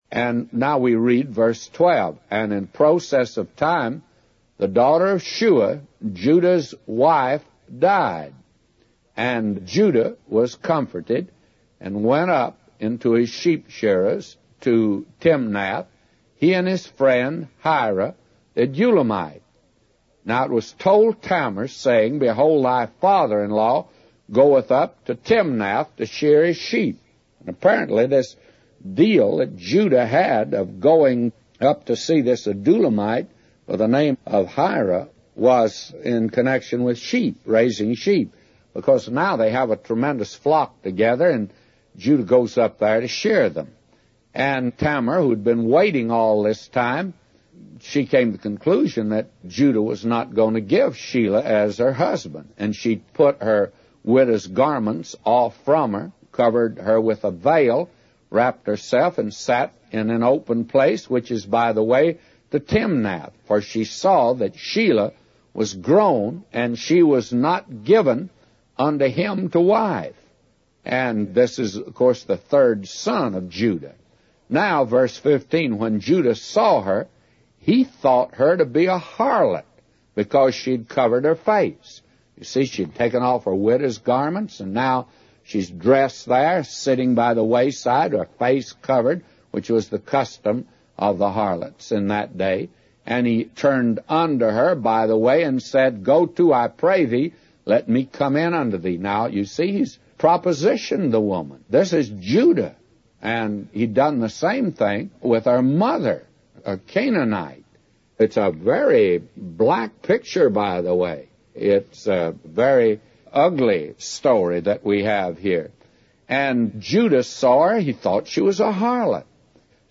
A Commentary By J Vernon MCgee For Genesis 38:12-999